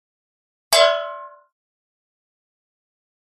Metal Pan Ping Hit, Type 3